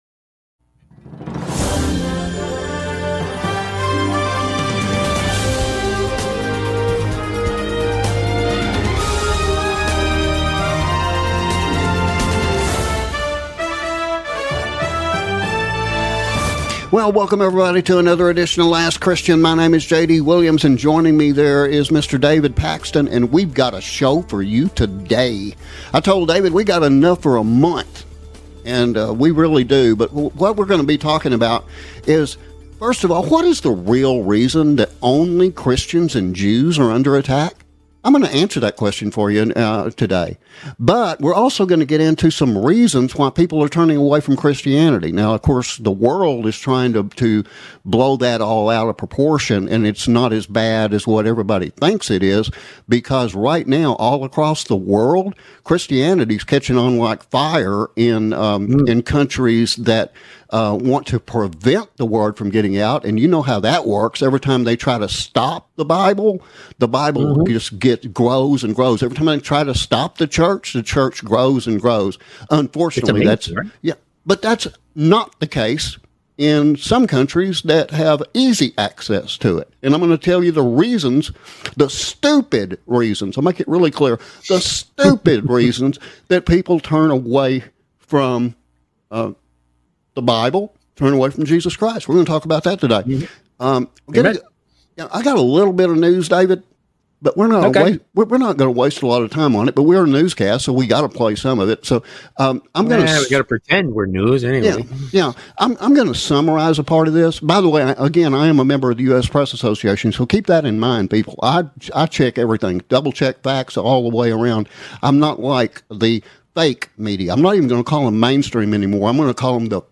These topics as well as Current Events in the Media Today are discussed in this edition of "Last Christian". The "Last Christian" is Presented every Tuesday, Thursday and Saturday evenings at 7:30pm Central on more than 50 Radio Stations, and broadcast to all 50 US States and more than 160 Countries around the World.